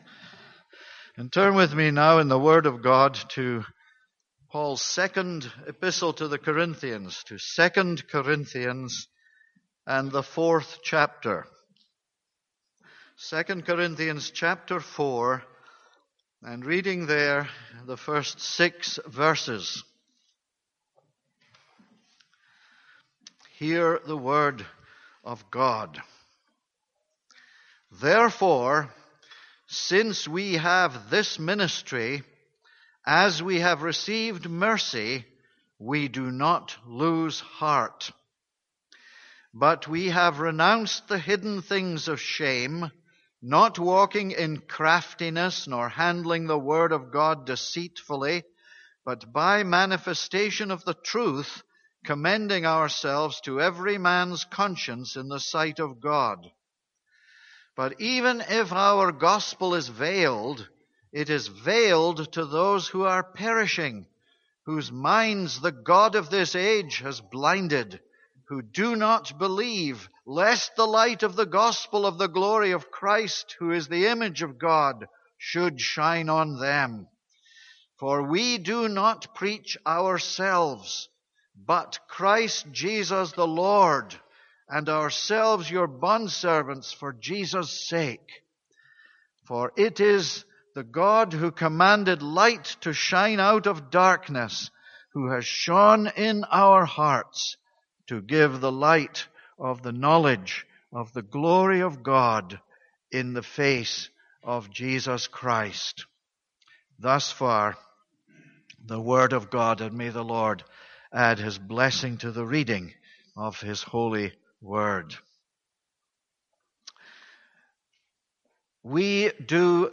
This is a sermon on 2 Corinthians 4:1-6.